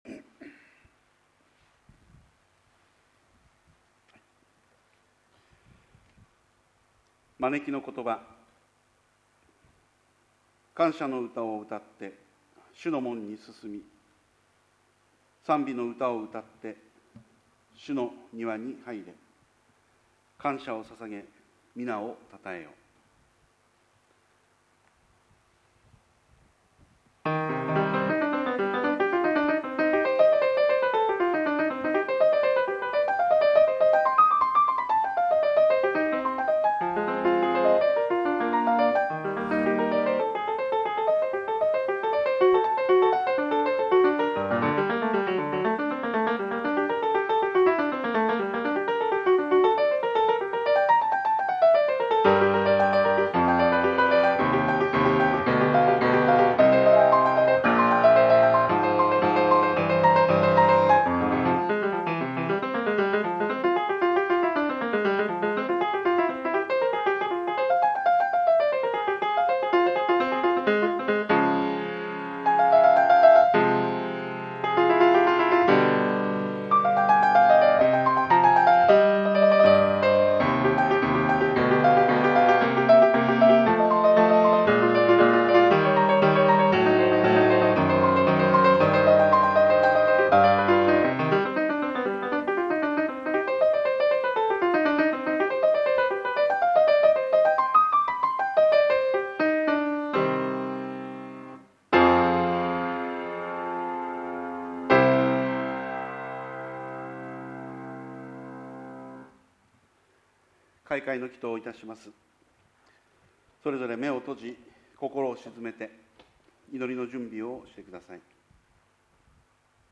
主 日 礼 拝 2020年 6月 7日 10時30分～
************************************************ ＜ 音声のみ＞ 礼拝全体の録音になっています。 マイク音声のため音質良好！